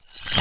casting_charge_matter_fast_01.wav